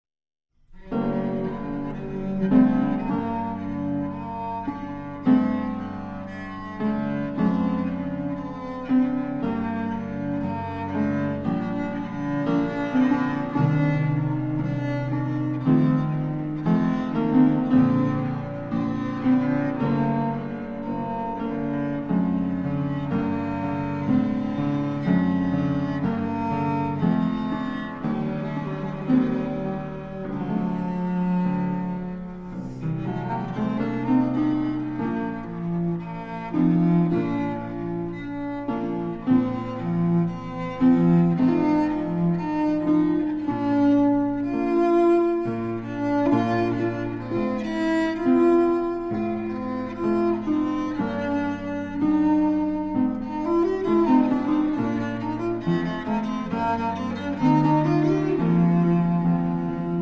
World premiere recording of the accompanied cello sonatas
Harpsichord
Classical Cello